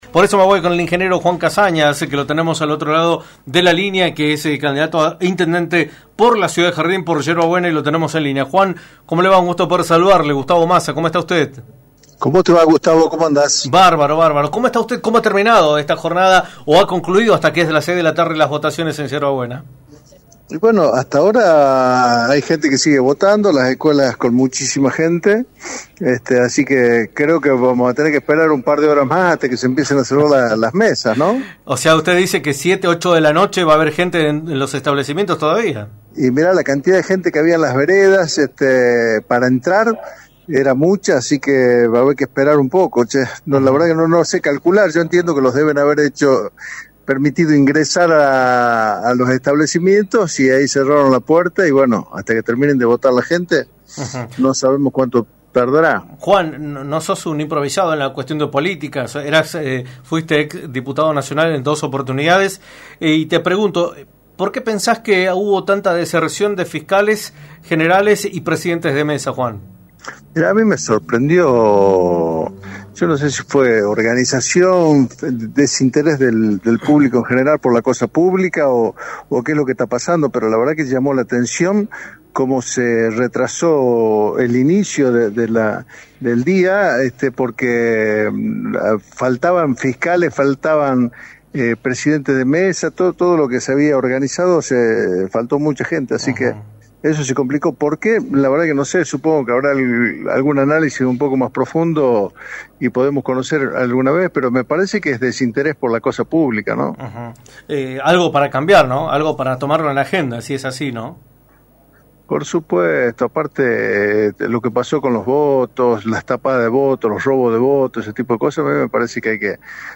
en entrevista para Radio del Plata Tucumán, por la 93.9.